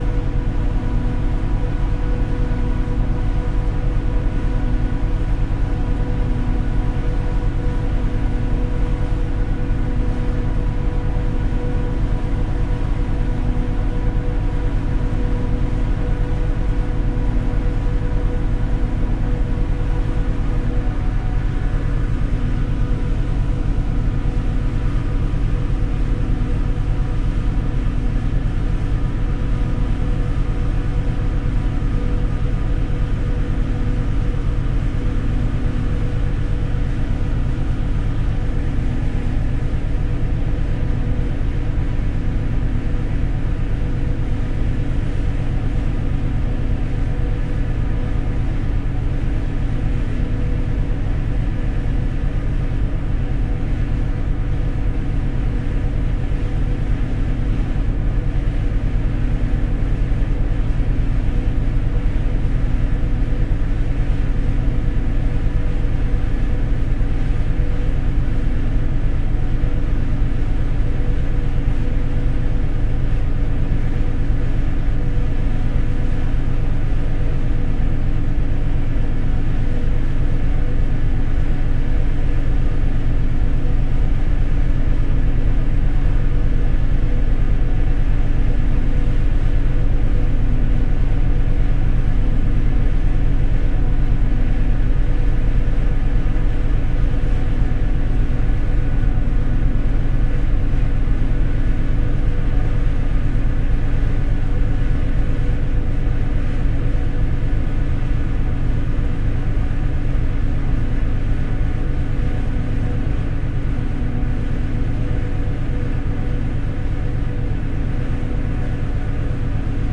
船只吱吱作响
描述：这种声音效果是船上的各种吱吱声